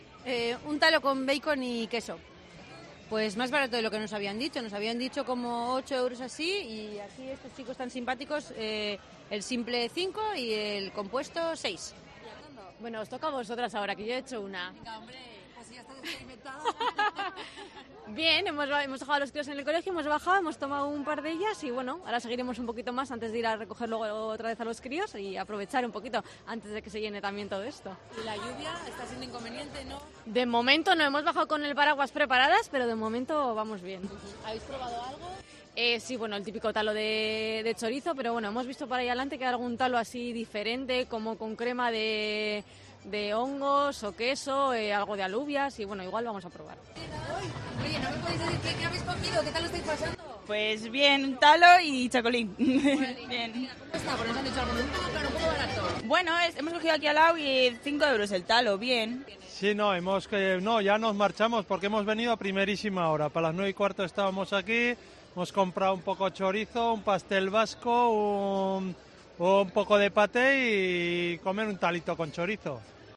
COPE País Vasco en la feria de Santo Tomás